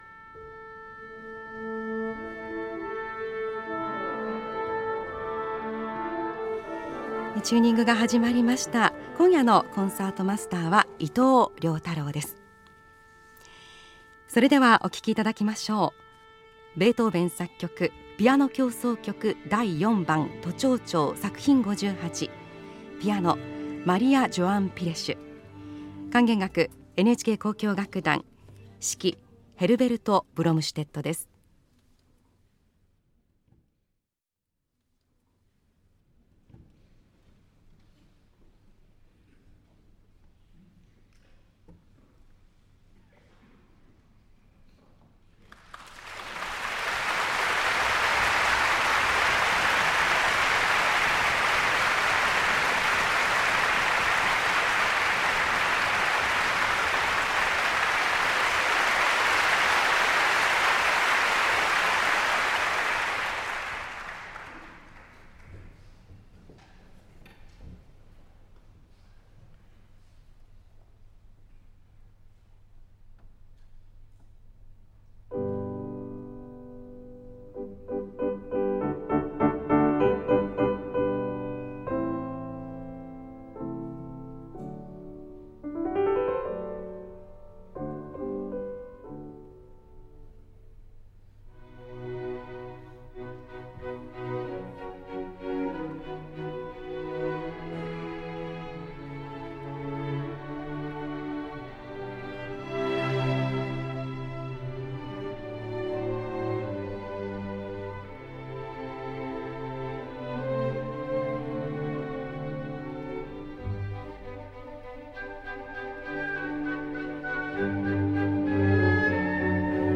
Maria João Pires, Piano – NHK Symphony – Herbert Blomstedt, guest Conductor – April 20, 2018 – Gordon Skene Sound Collection –
recorded live only a few days ago by NHK in Tokyo
Recorded on April 20th at a subscription concert, the NHK Symphony, guest conducted by Herbert Blomstedt play an all-Beethoven concert. Beginning with the Piano Concerto Number 4, followed by a standing ovation and encore of Bagatelle Number 5 from Six Bagatelles op.126 and concluding the concert with the Symphony Number 4.